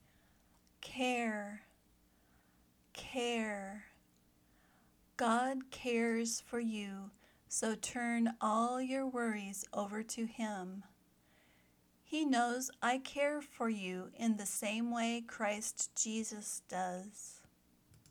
/ker/  (verb)